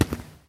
脚步声" 混凝土脚步声2
描述：一个混凝土脚步的录音。
Tag: 脚步 步骤 步骤 混凝土 脚步